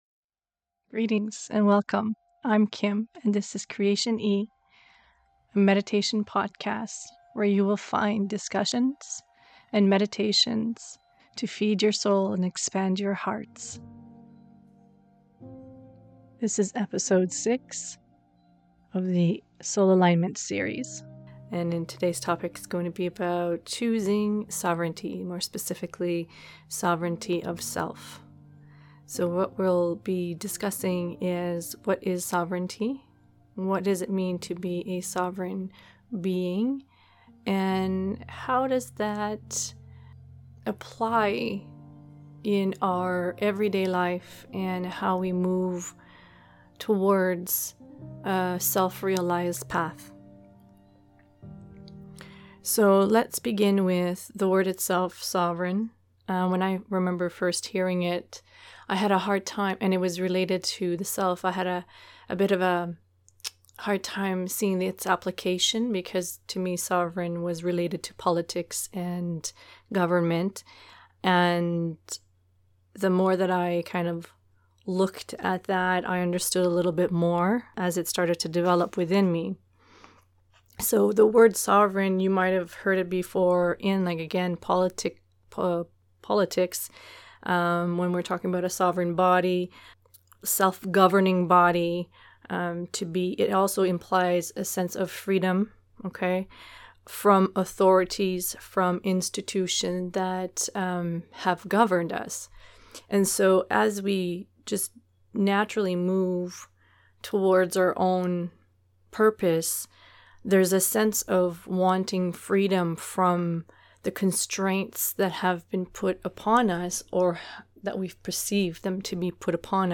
Key topics in this conversation include: Creator consciousness, choosing the path of purpose, removing illusions, being the authority of self. The guided meditation that follows opens you to a greater state of consciousness as